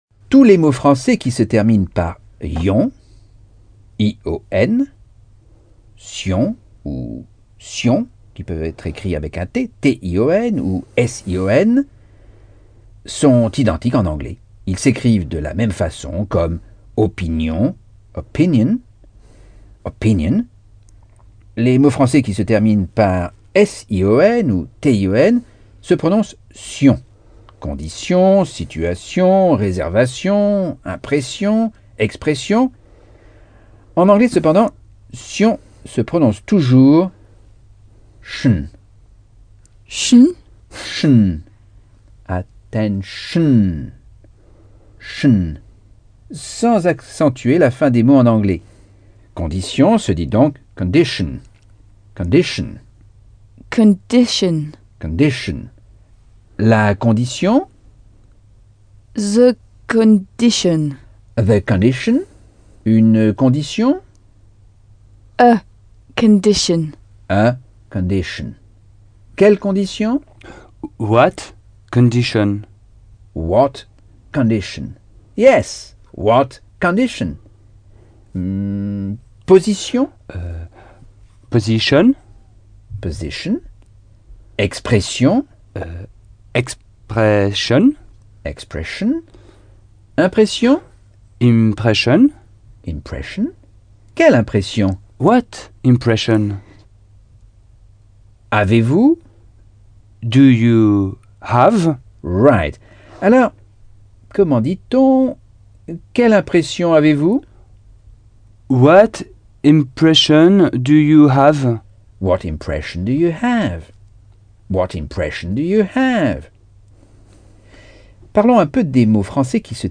Leçons 13 - Anglais audio par Michel Thomas